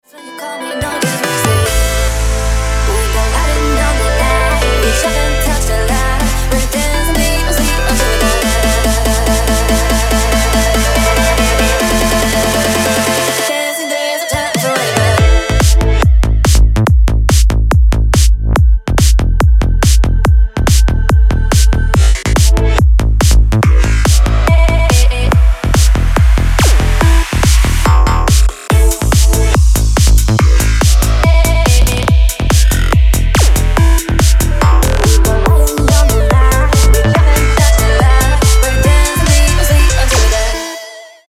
• Качество: 320, Stereo
Electronic
нарастающие
быстрые
psy-trance
Представитель не слишком частого жанра psy-trance